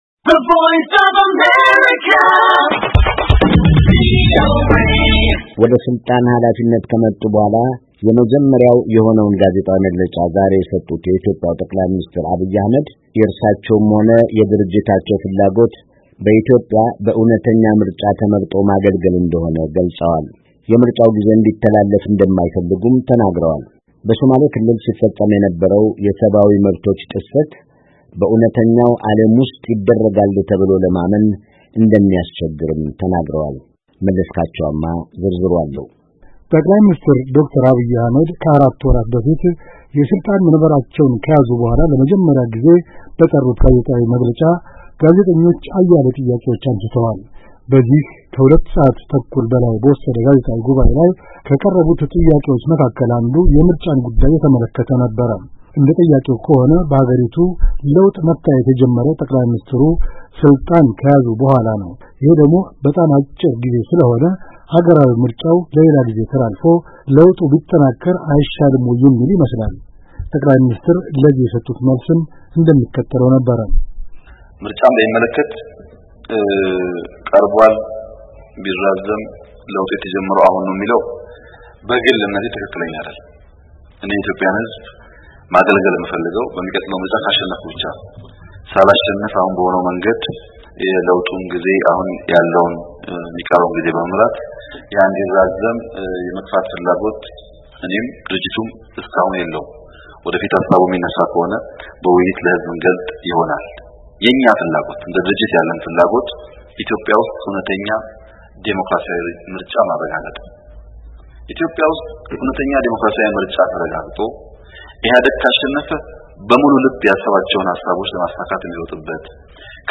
ጠ/ሚ አብይ አሕመድ ለጋዜጠኞች የሰጡት መግለጫ